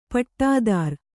♪ paṭṭādār